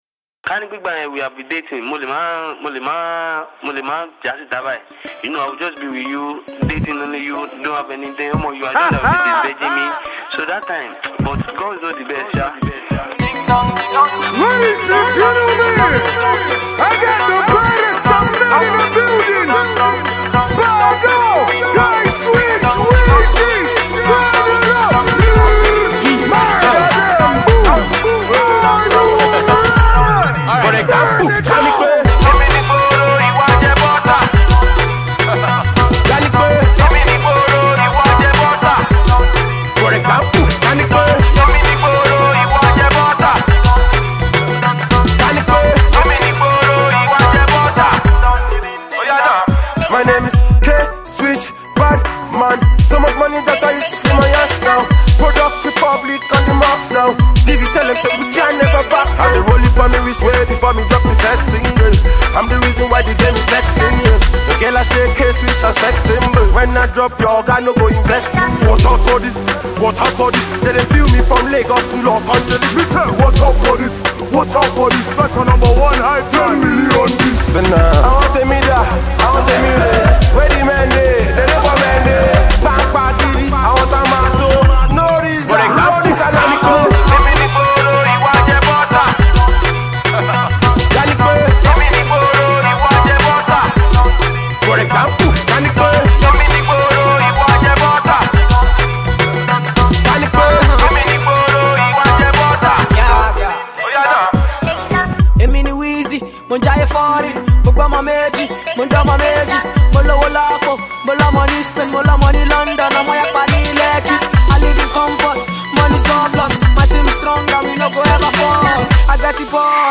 for the remix of his street anthem